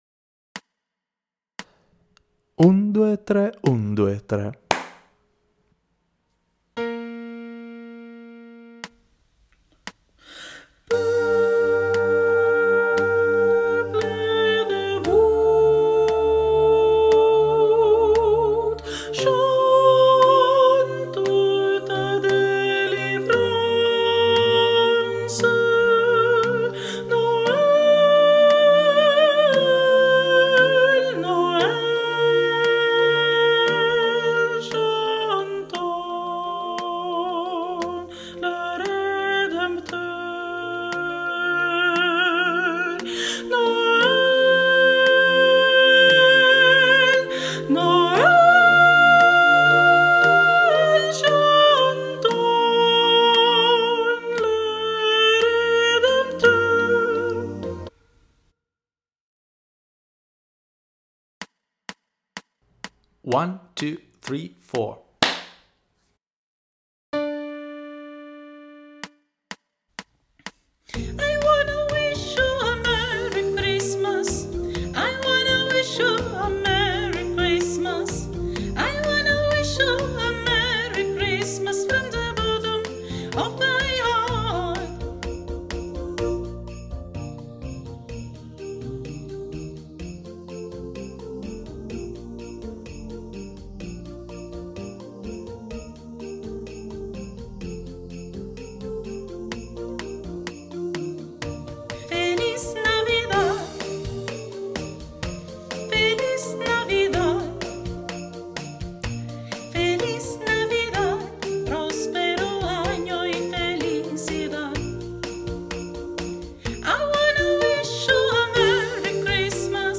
Per partecipare alla nostra iniziativa dovresti studiare la canzone di Natale di Feniarco: un arrangiamento con 10 melodie natalizie, che si alternano tra i diversi registri vocali, realizzato appositamente per l'occasione da Alessandro Cadario.
Mp3 traccia guida coro unisono (TUTTI, versione femminile)